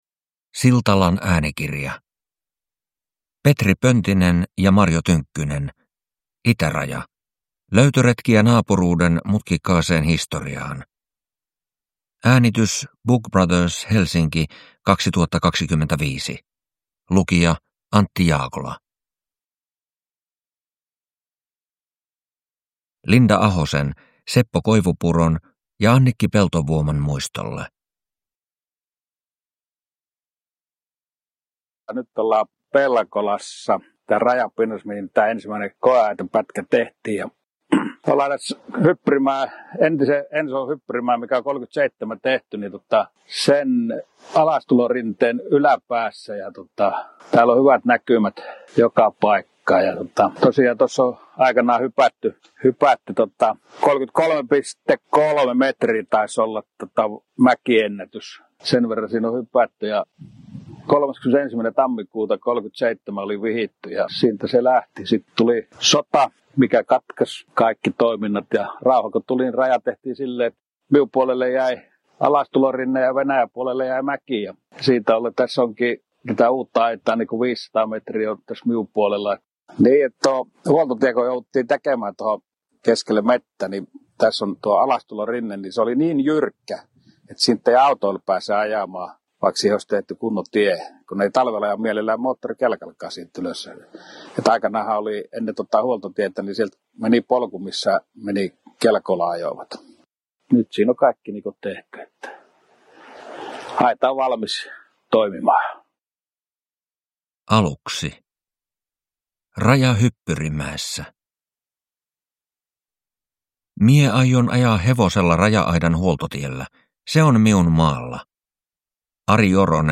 Itäraja – Ljudbok